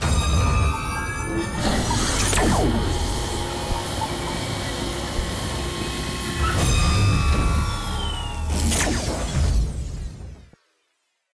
decontamination.ogg